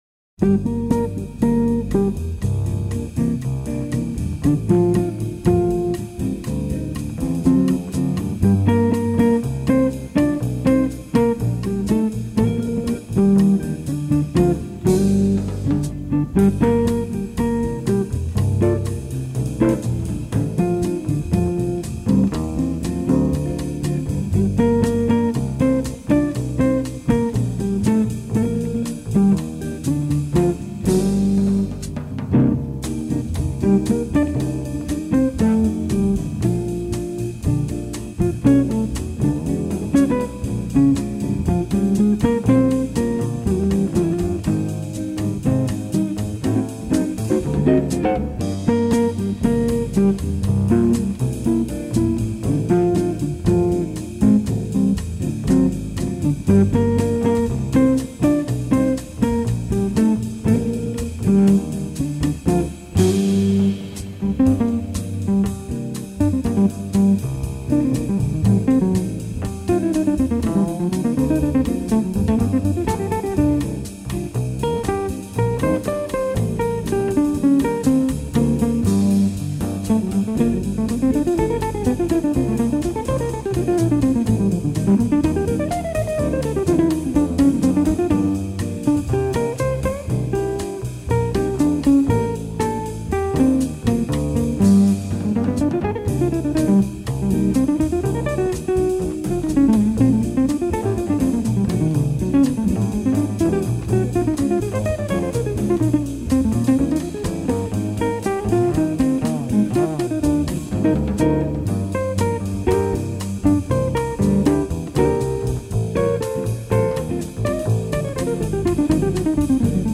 bumpy yet soft music